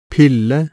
Sterkest aspirasjon finner man etter de ustemte plosivene , og når de er først i en trykksterk stavelse, som i pille:
Bølgeforma til en sørøstnorsk uttale av ordet pille .